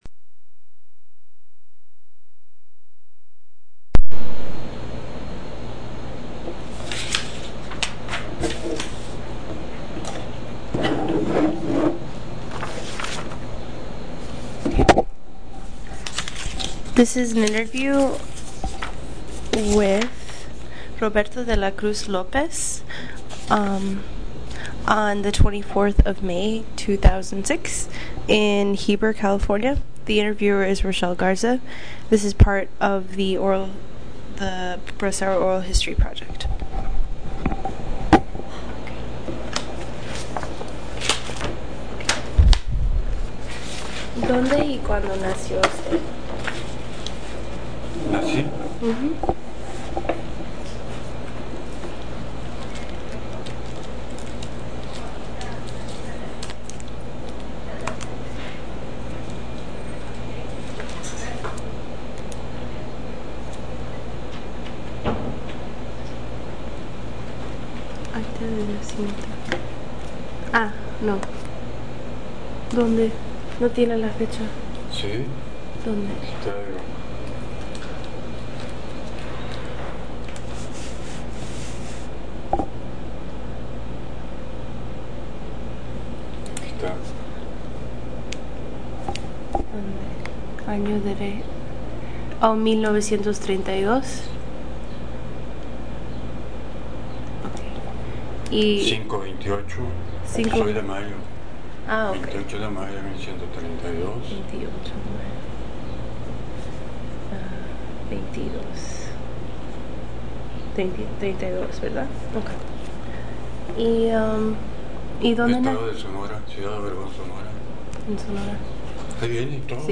Original Format Mini Disc